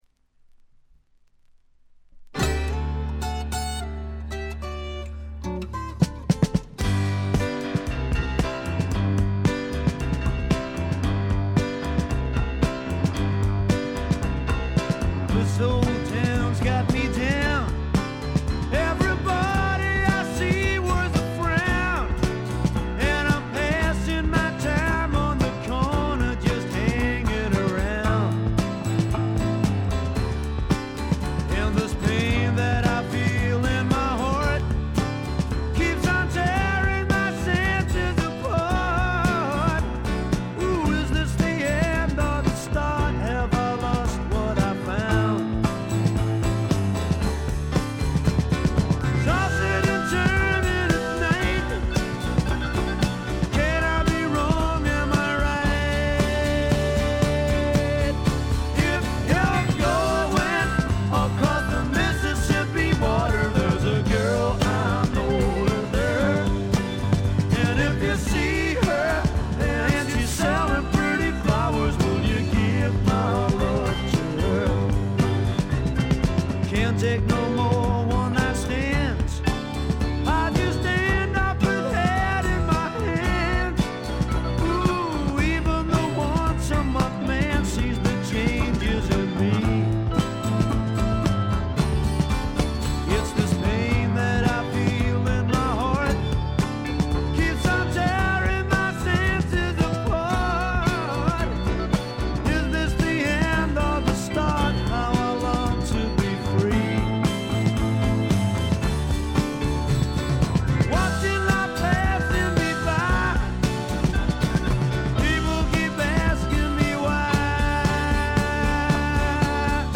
部分試聴ですが、静音部での軽微なバックグラウンドノイズ程度。
なにはともあれ哀愁の英国スワンプ／英国フォークロック基本中の基本です。
試聴曲は現品からの取り込み音源です。